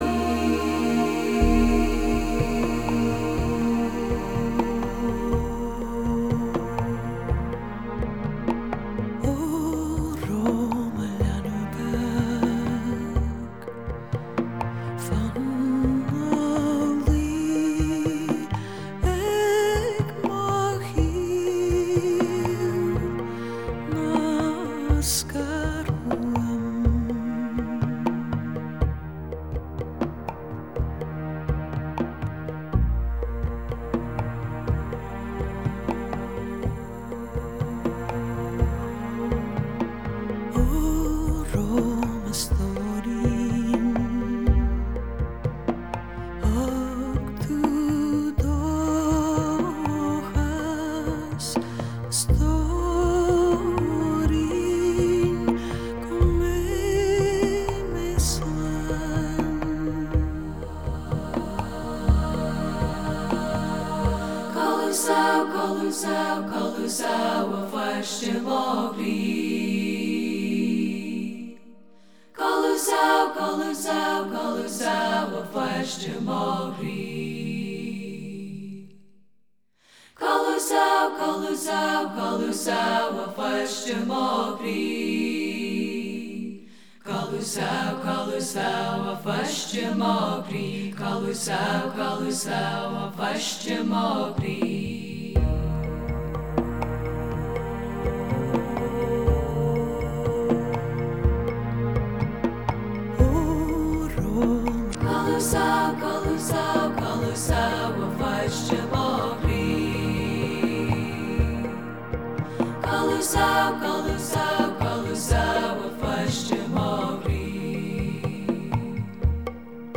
Электронная музыка Фолк музыка Фолк Рок